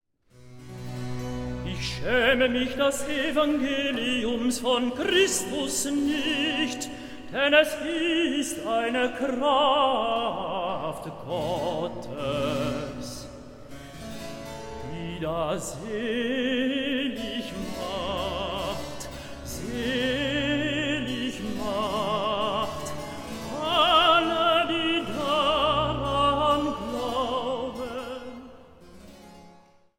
orgel
piano
sopraan
tenor
bariton